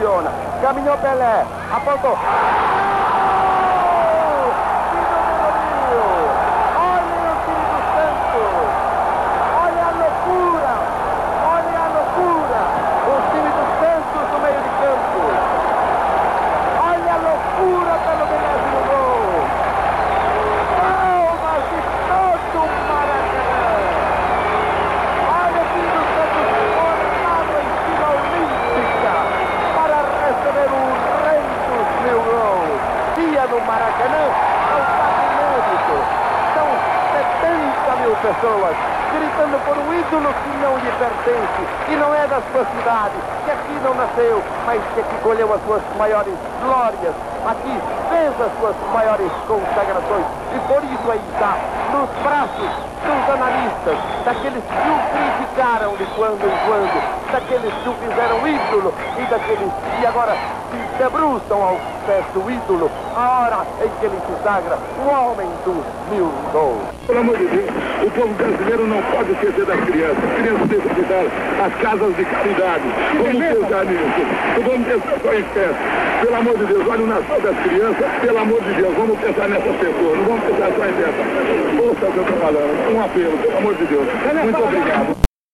Confira algumas narrações de gols importantes para a carreira do maior jogador de futebol de todos os tempos: